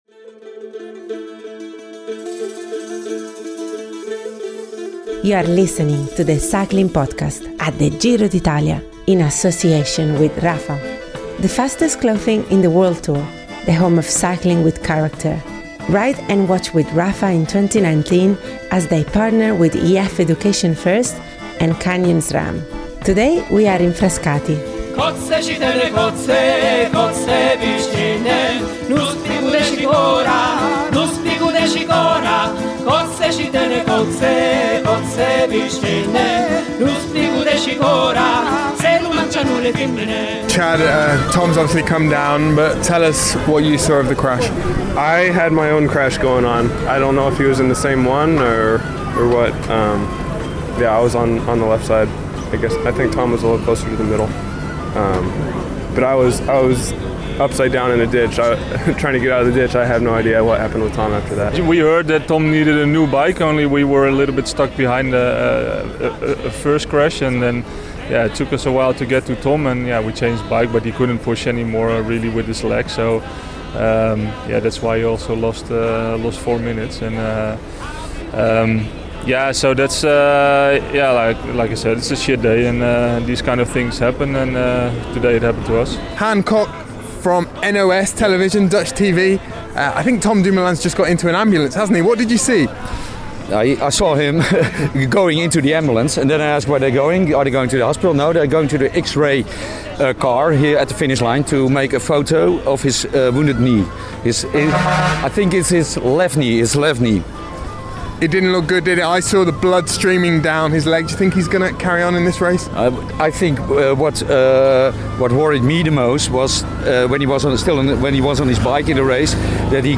We hear from Australian Caleb Ewan of Lotto-Soudal who was one of only a dozen or so to dodge both late crashes and who set off in pursuit of Carapaz in the final three hundred metres.